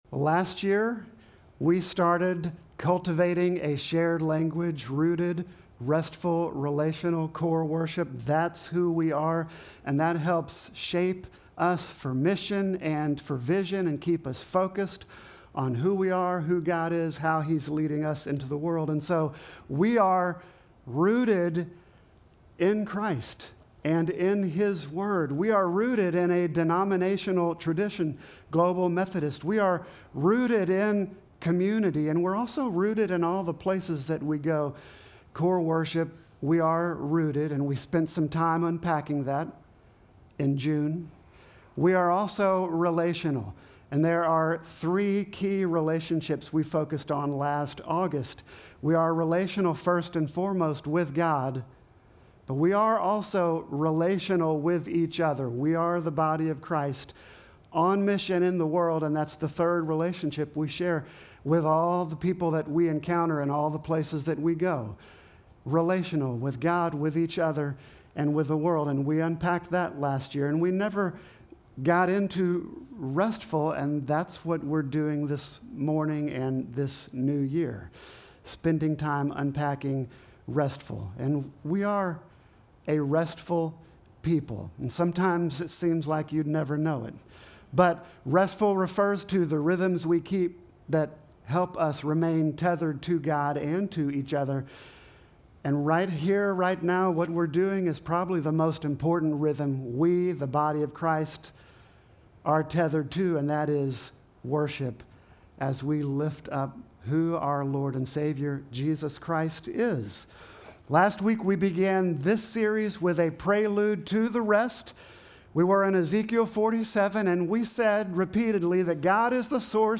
Sermon text: Matthew 11:27